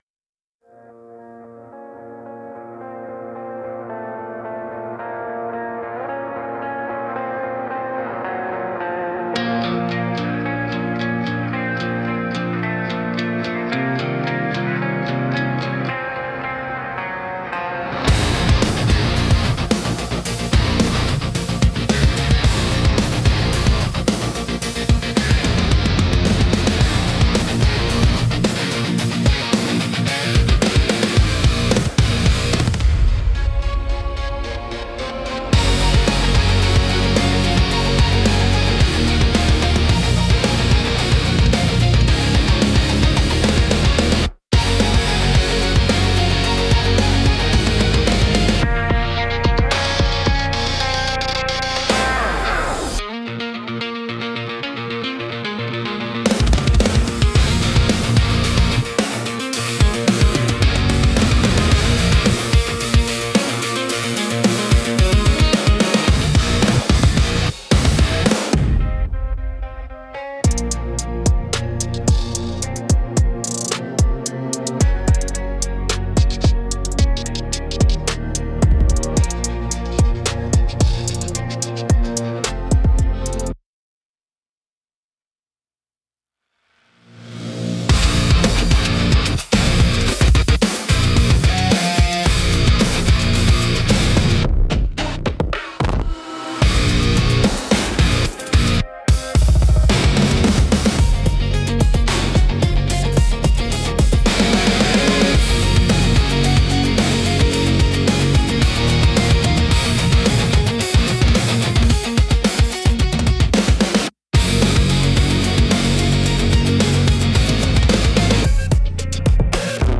Instrumental - Slow Thunder on a Six-String